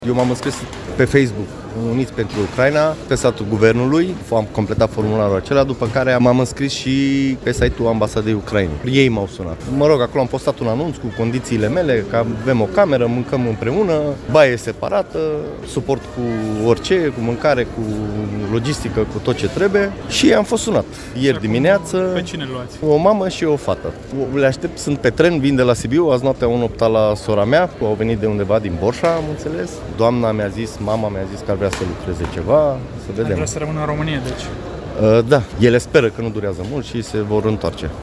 Reportaj. În Gara de Nord, refugiații au timp să respire, iar copiii se pot juca din nou, înainte de a pleca spre o altă destinație | AUDIO